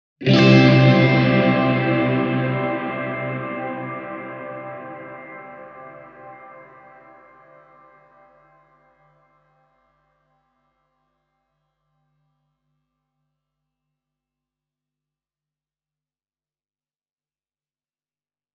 Plate-kaiku on toinen Supernaturalin kahdesta perinteisestä kaikutyypistä:
Muut audiopätkät on soitettu kaikki säätimet keskiasennolla, mutta seuraavalla Plate-pätkällä Mix-säädin on kello kolmessa ja Decay avattu täysille:
digitech-hardwire-supernatural-e28093-plate-full-decay.mp3